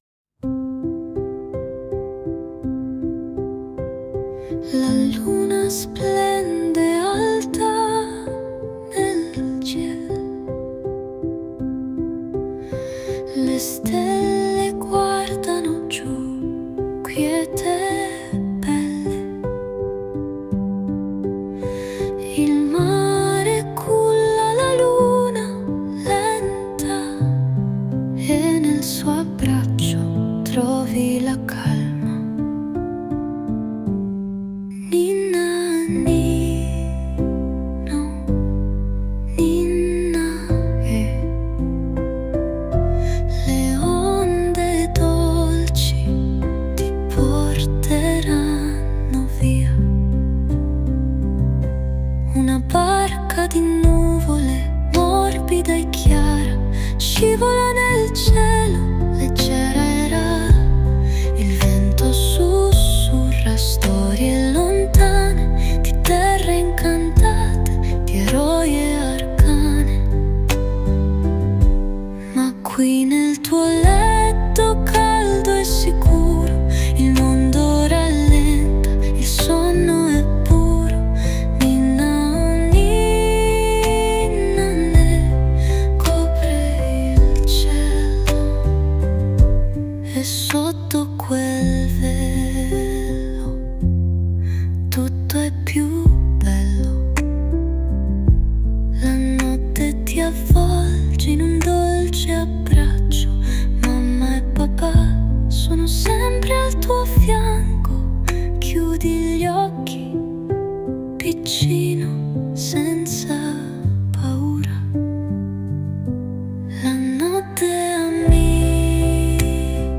Scopri la magia delle ninna nanne, canti popolari che accompagnano dolcemente i sogni dei bambini, avvolgendo le notti in un abbraccio di serenità.
ninna-nanna-onde.mp3